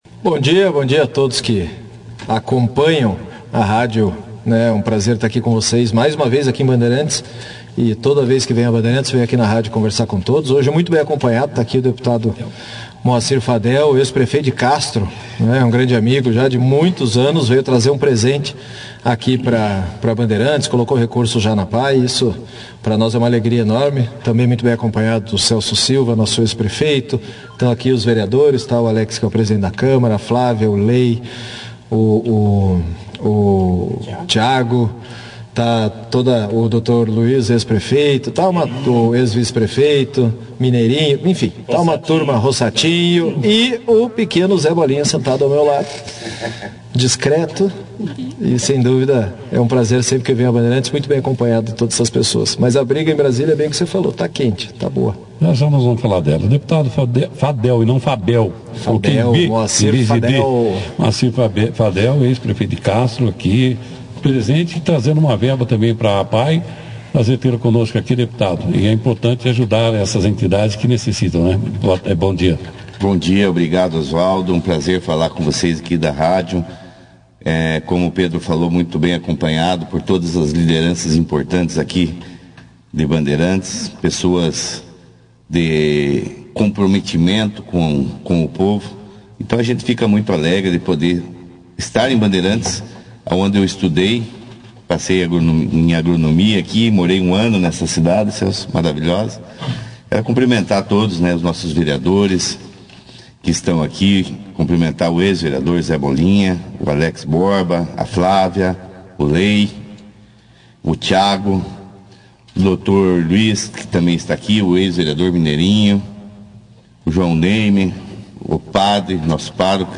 Em seguida, os parlamentares participaram de uma entrevista ao vivo nos estúdios da Cabiuna FM, onde Lupion agradeceu a presença dos vereadores que o acompanharam na emissora, incluindo o presidente da Câmara, Alex Borba, e os vereadores Lei das Três Águas, Flávia Silva e Thiago Pobreza.